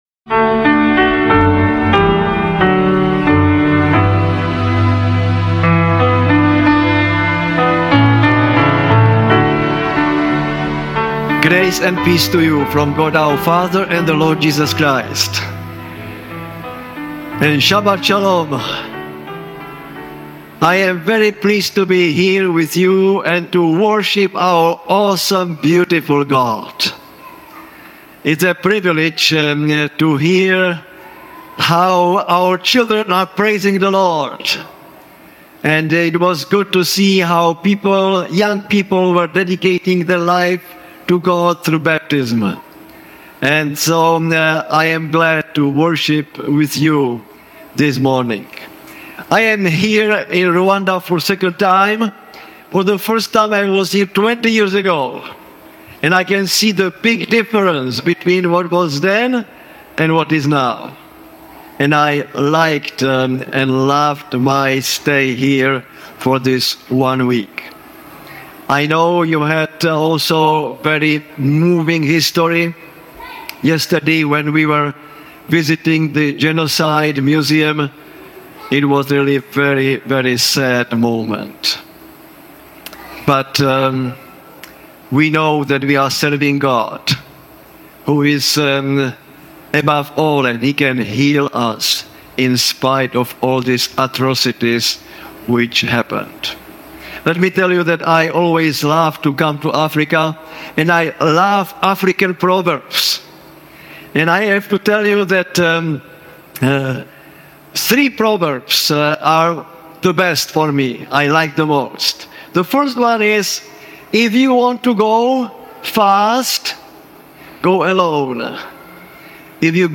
This sermon invites you beyond religious routine into a living, transforming relationship with God—where grace, not effort, shapes true spiritual radiance. Through Moses’ story, deep biblical insights, and even a pumpkin metaphor, you'll discover how humility, intercession, and communion with God lead to lasting inner change.